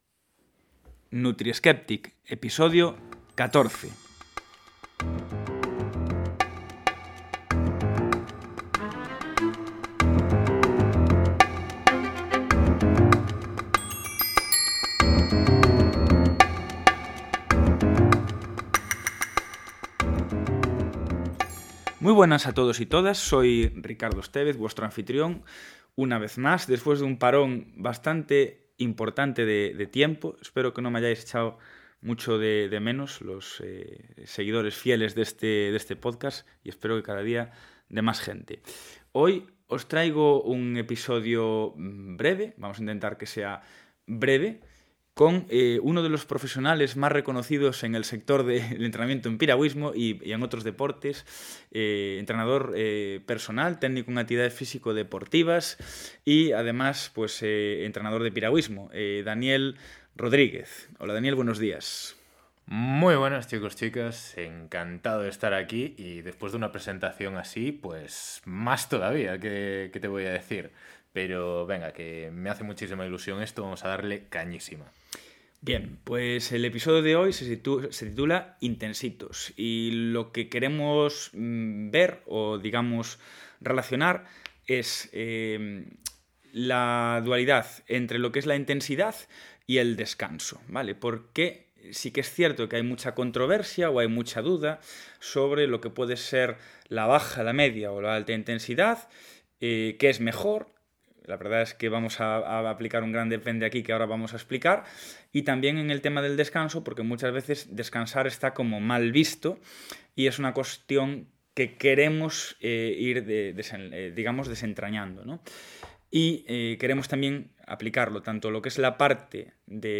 en una conversación cercana y sin postureo, pensada para gente que entrena y quiere aplicar sentido común a sus decisiones.